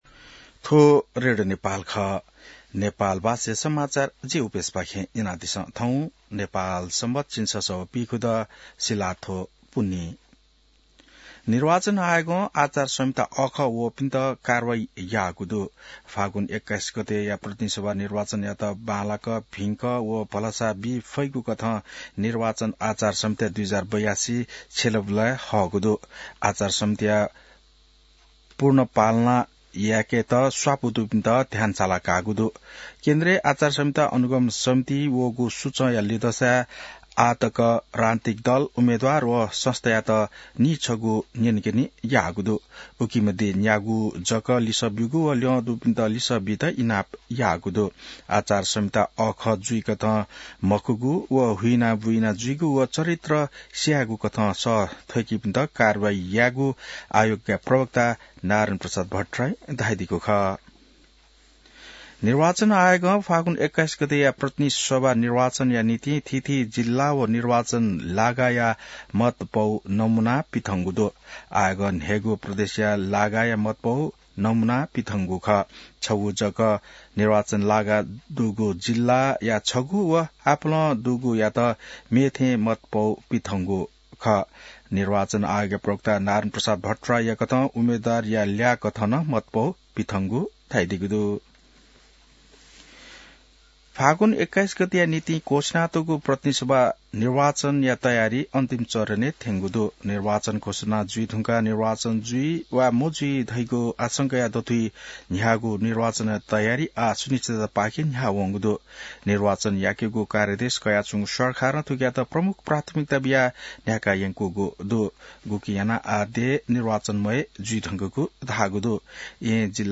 नेपाल भाषामा समाचार : १८ माघ , २०८२